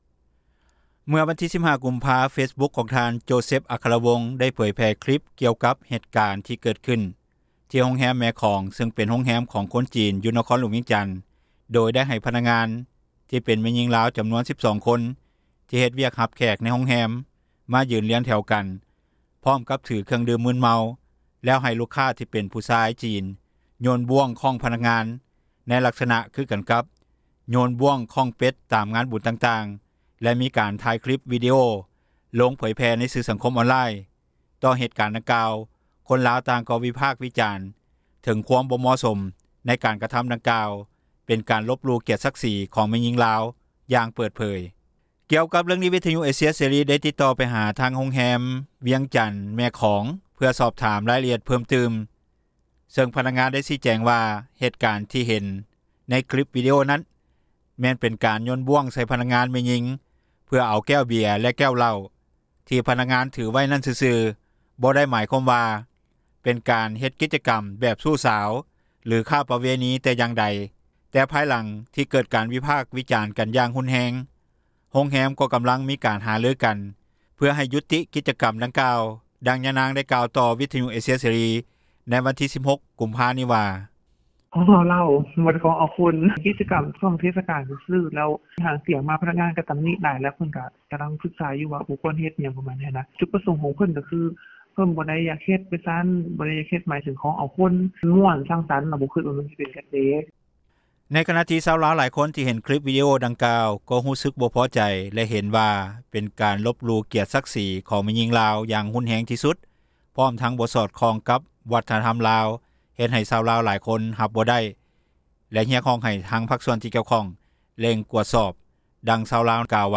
ດັ່ງຍານາງ ໄດ້ກ່າວຕໍ່ວິທຍຸເອເຊັຍເສຣີ ໃນວັນທີ 16 ກຸມພາ ນີ້ວ່າ: